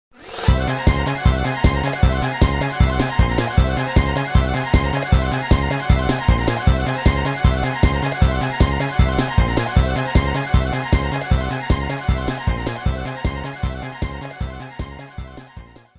theme
This is a sample from a copyrighted musical recording.